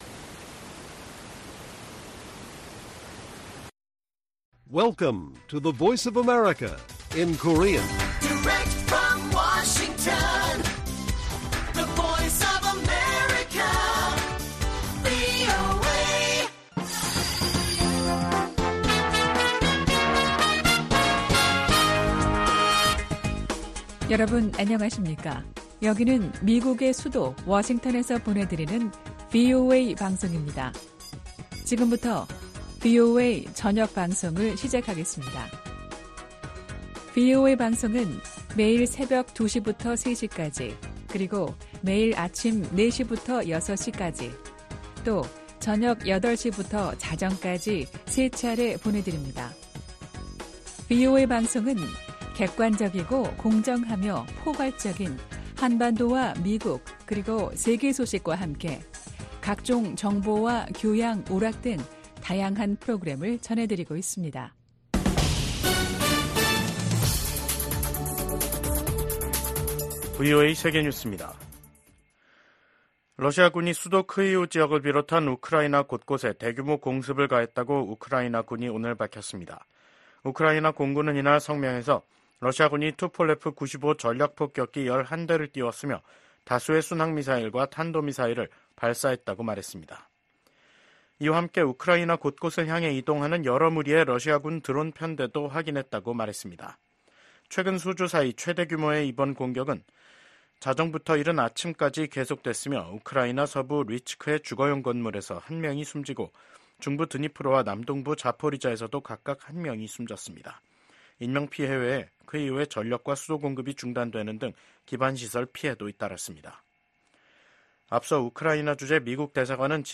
VOA 한국어 간판 뉴스 프로그램 '뉴스 투데이', 2024년 8월 26일 1부 방송입니다. 미국 백악관 국가안보보좌관이 중국을 방문해 북한 문제 등 미중 현안을 논의합니다. 북한이 김정은 국무위원장의 현지 지도 아래 자신들이 만든 자폭형 무인공격기를 처음 공개했습니다.